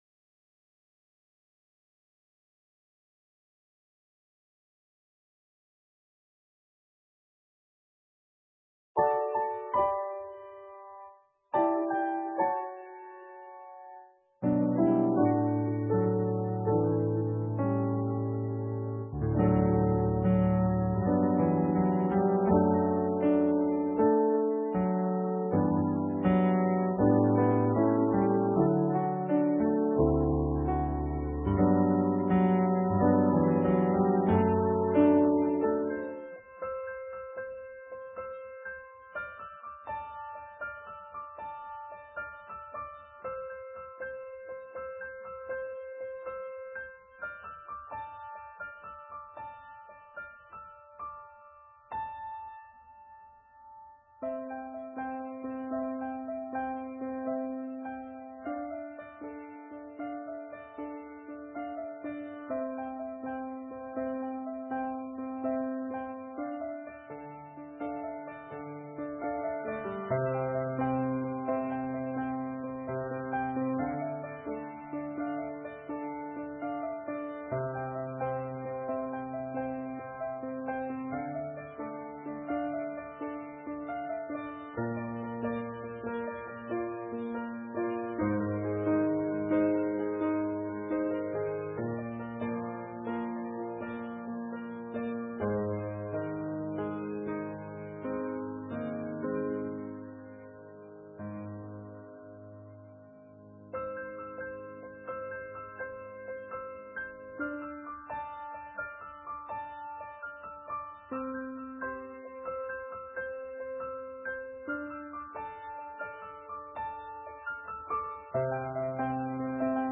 Note: The prelude begins 15 minutes into the video and the audio file